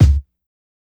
KICK_RIGHTPLACE.wav